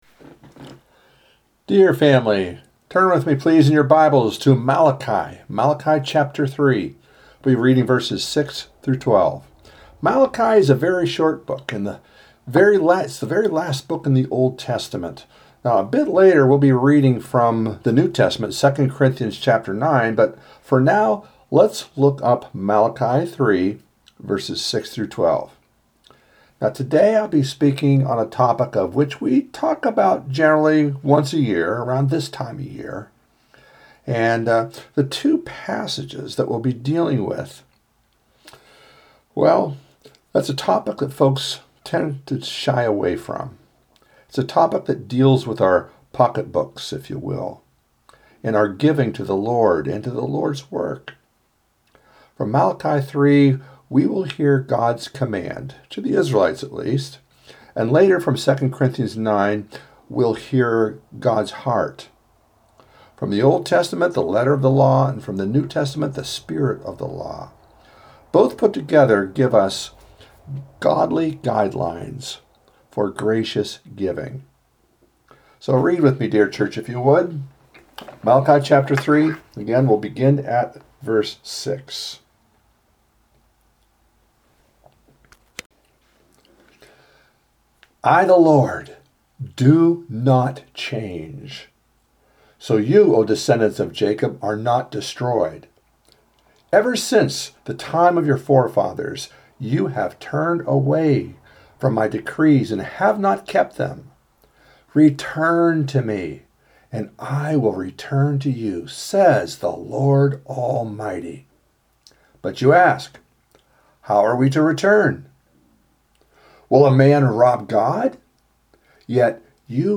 Worship and Sermon – February 9, 2025